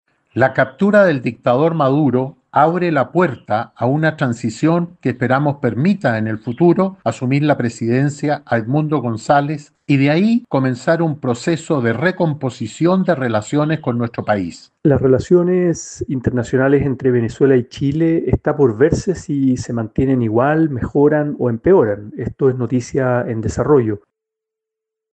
De hecho, el presidente de la Comisión de Relaciones Exteriores, Iván Moreira (UDI), dijo que la captura de Maduro es el primer paso para recomponer vínculos.
Por su parte, el senador frenteamplista Juan Ignacio Latorre advirtió que todo sigue en desarrollo.